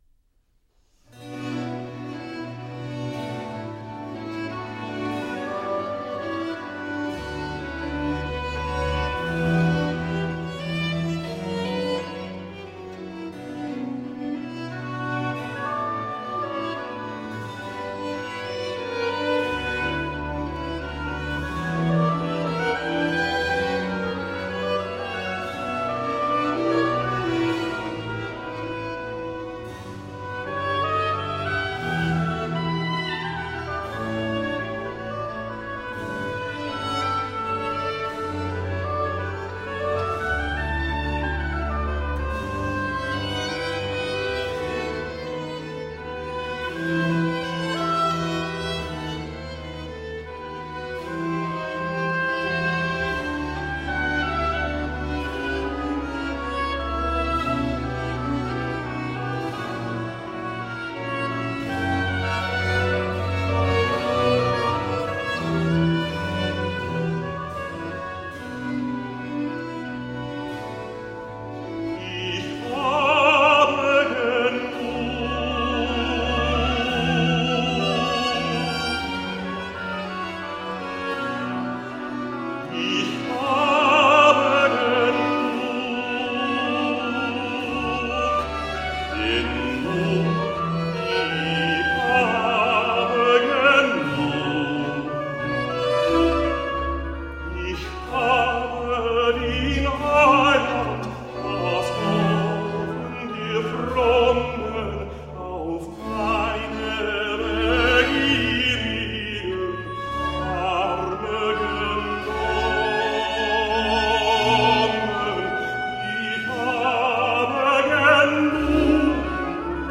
Lively, bright baroque .